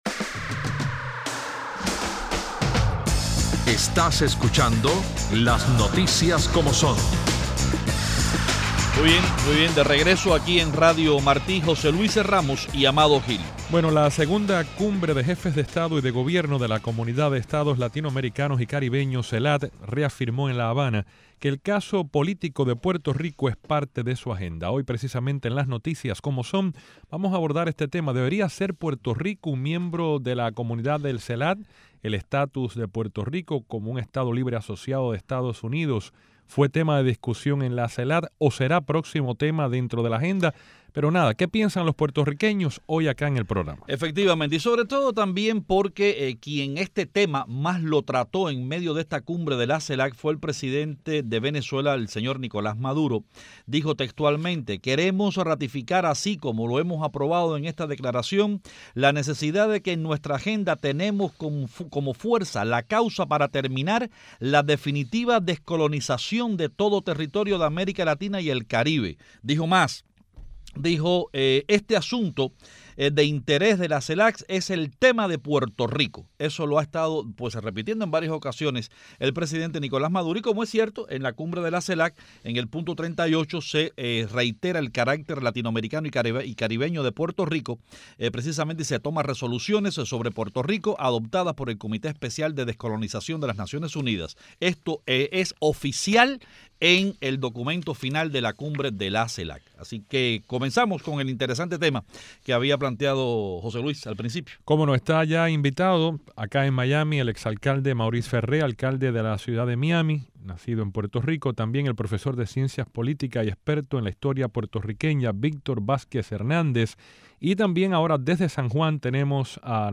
Desde Miami, nuestros invitados son el ex alcalde de Miami, Maurice Ferré